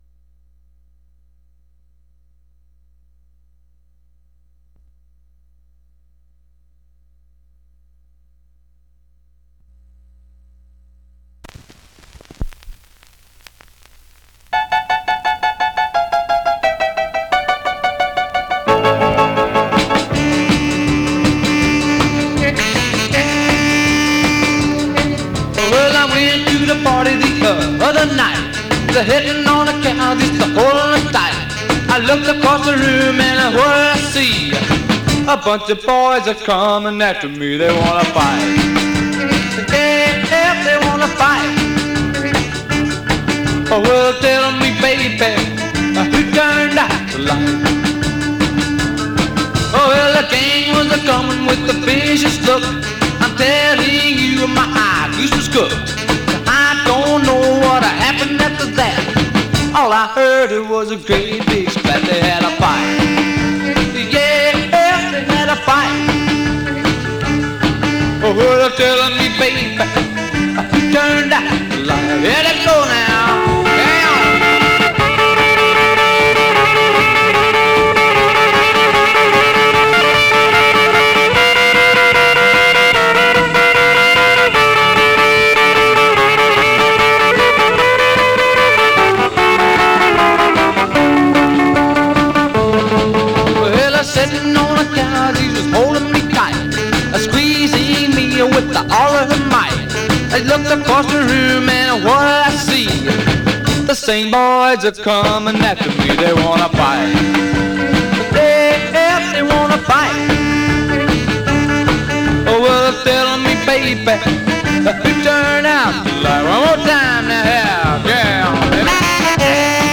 Some surface noise/wear
Mono
Rockabilly